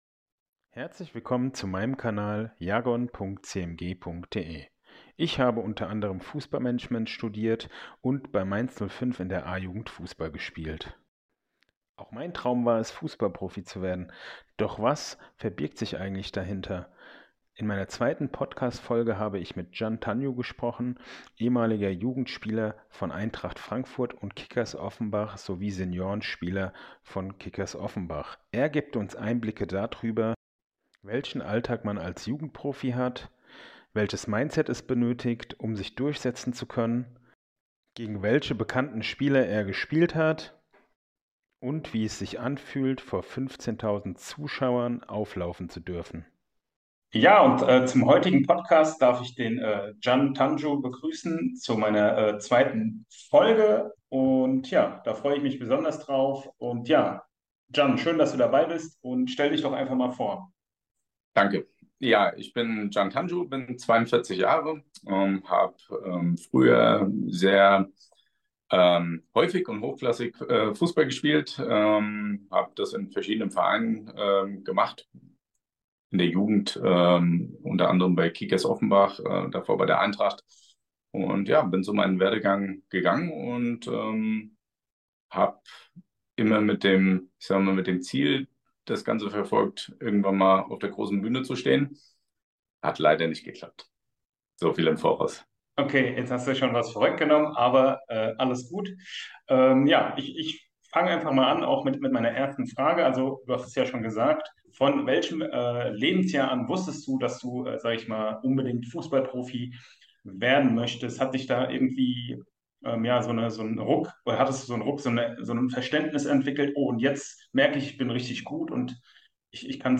Wenn euch das Podcast Interview gefällt, dann abonniert und bewertet gerne meinen Kanal auf Spotify, Amazon Music, Apple Podcasts oder Youtube.